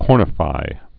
(kôrnə-fī)